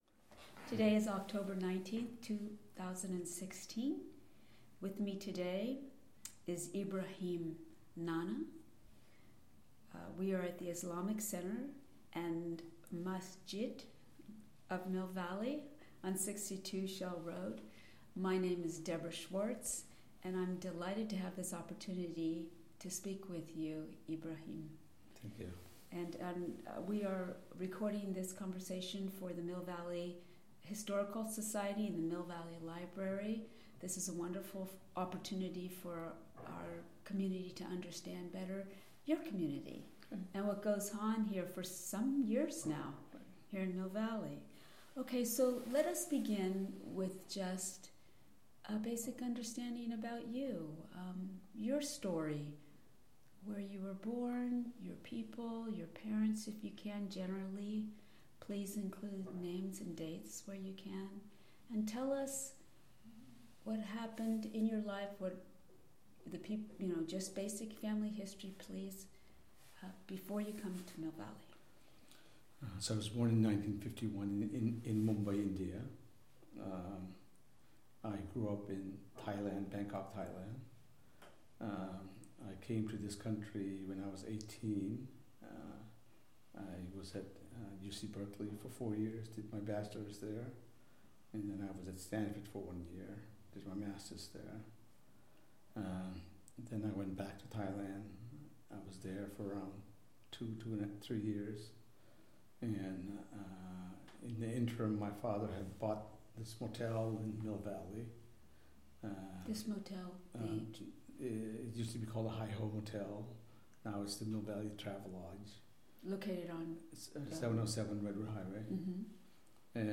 Oral History
Recorded at the Islamic Center of Mill Valley